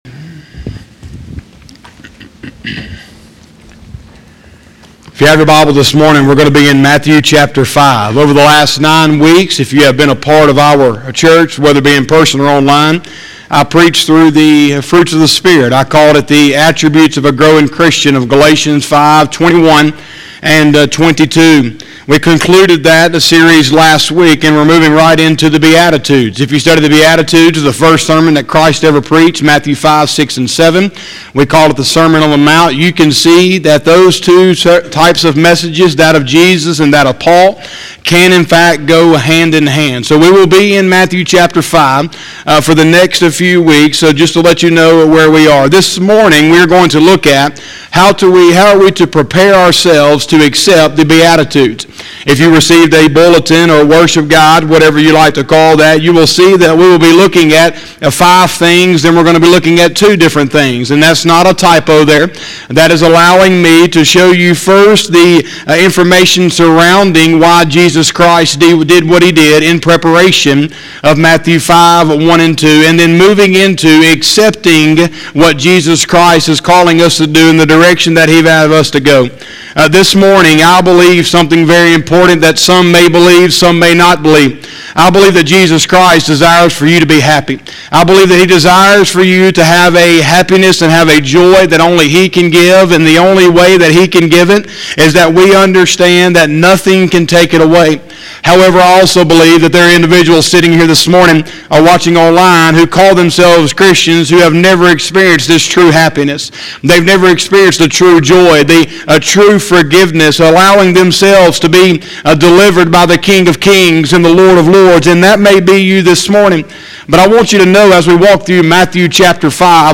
03/07/2021 – Sunday Morning Service